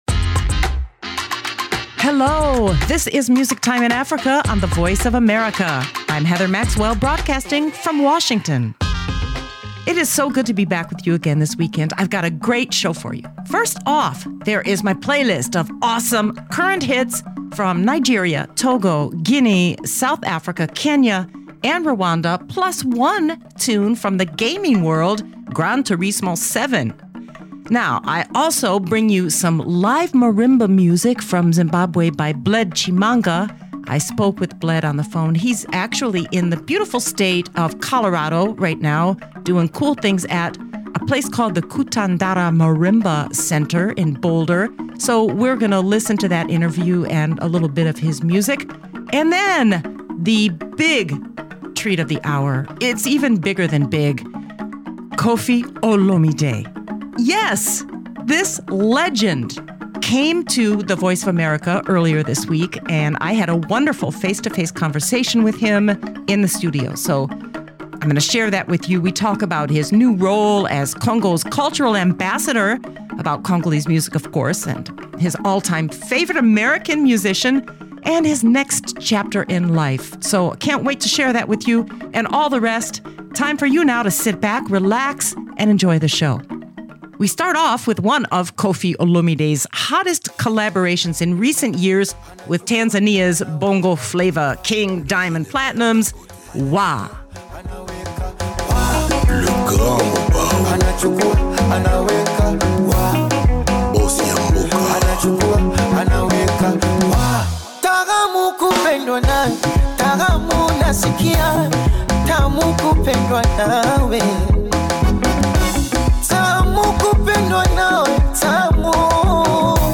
live guest at the Voice of America in Washington DC. They talk about his new role as Congo’s Cultural Ambassador, about Congolese music, his all-time favorite American musician … and his next chapter in life.
interviews